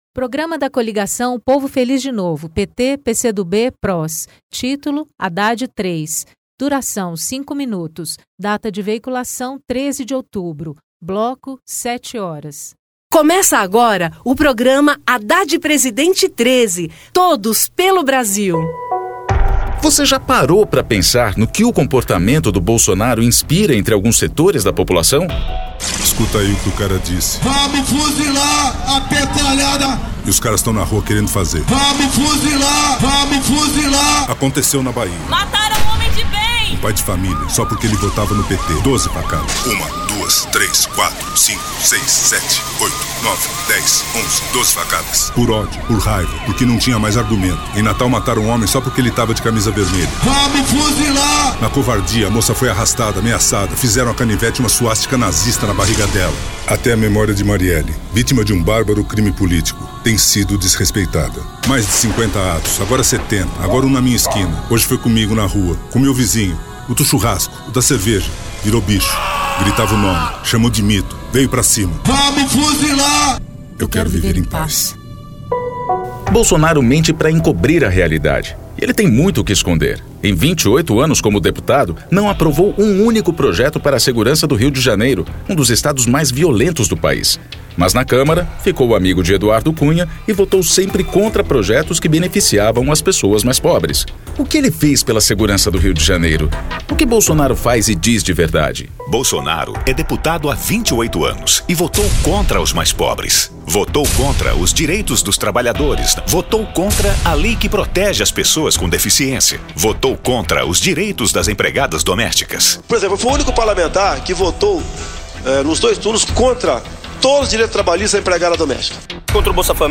TítuloPrograma de rádio da campanha de 2018 (edição 33)
Gênero documentaldocumento sonoro